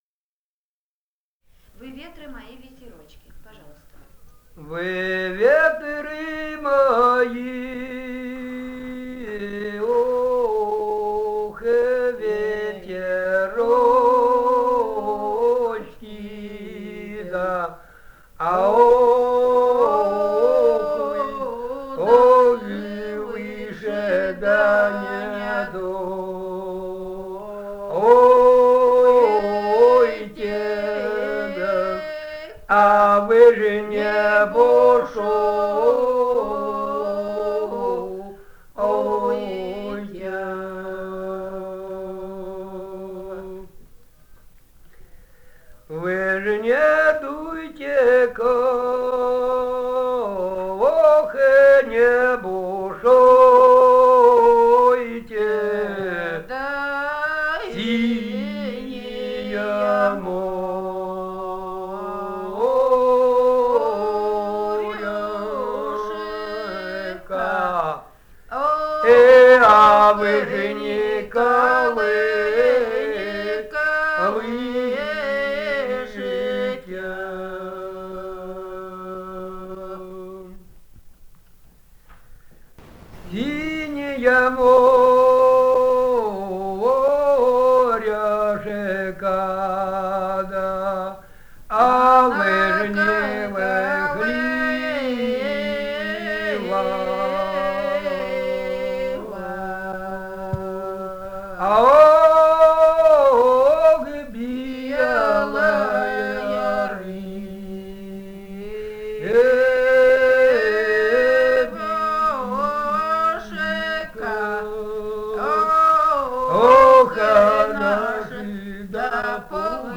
Этномузыкологические исследования и полевые материалы
Алтайский край, с. Михайловка Усть-Калманского района, 1967 г. И1001-10